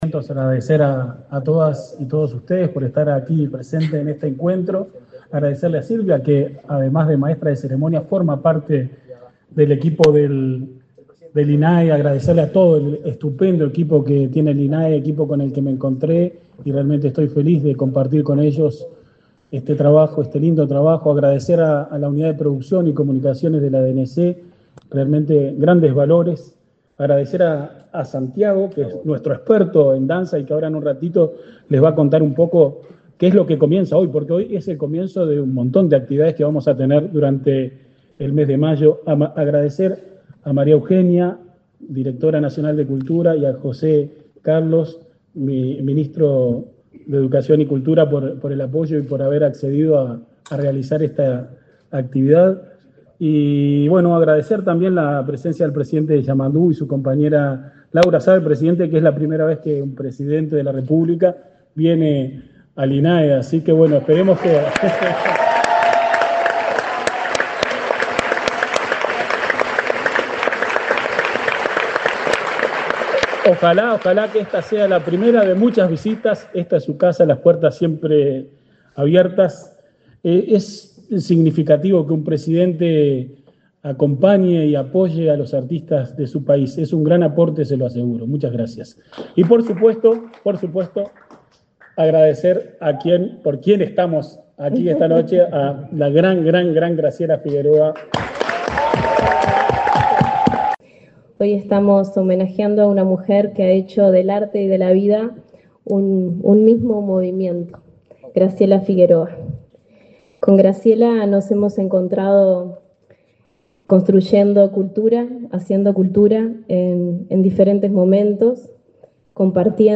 Palabras de autoridades en acto en el INAE
Palabras de autoridades en acto en el INAE 29/04/2025 Compartir Facebook X Copiar enlace WhatsApp LinkedIn El coordinador del Instituto Nacional de Artes Escénicas (INAE), Gustavo Zidan; la directora nacional de Cultura, María Eugenia Vidal, y el ministro de Educación y Cultura, José Carlos Mahía, se expresaron durante la ceremonia de entrega del premio INAE a Graciela Figueroa, por su trayectoria en la danza.